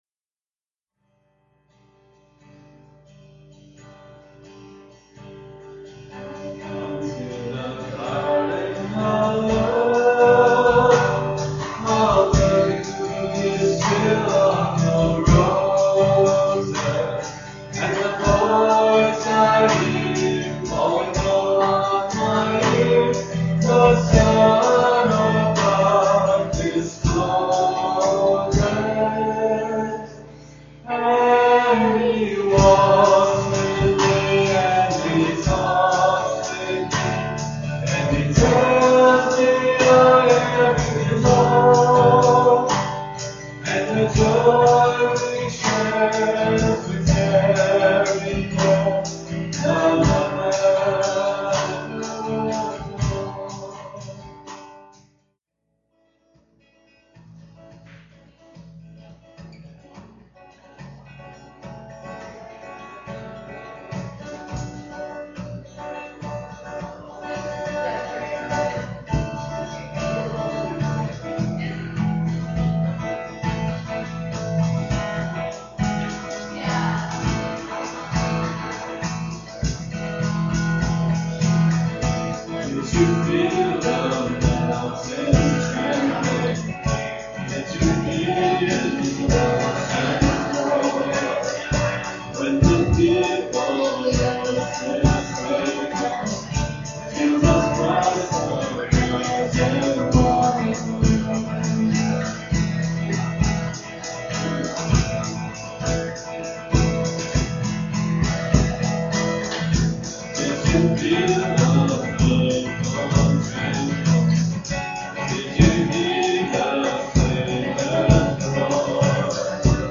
at Ewa Beach Baptist Church
guitar and vocals
drums.